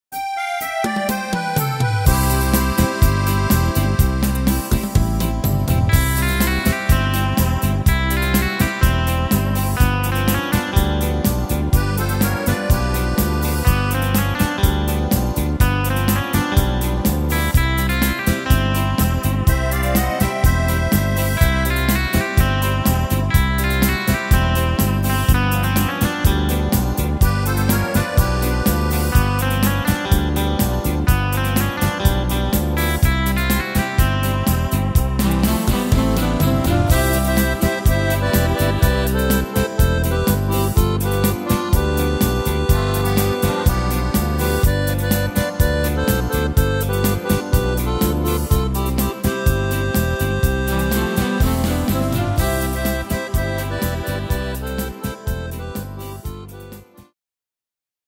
Tempo: 124 / Tonart: C-Dur